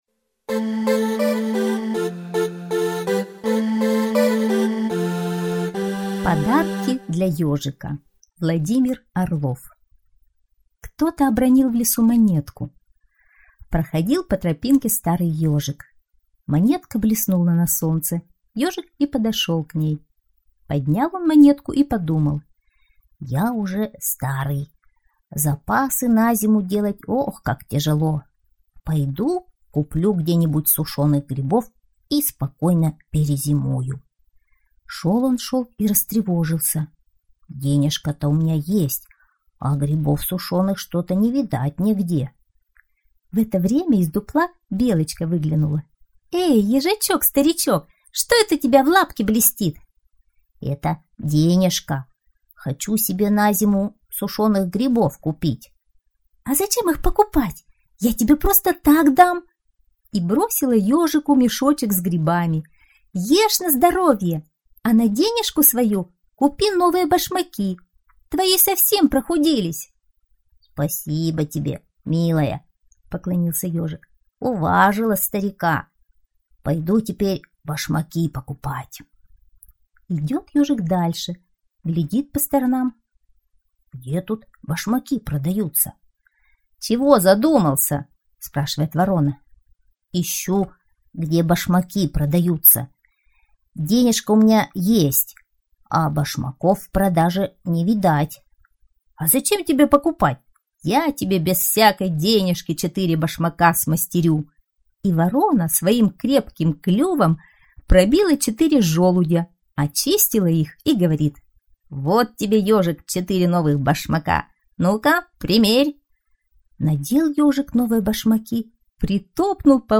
Подарки для ёжика - аудиосказка Орлова - слушать онлайн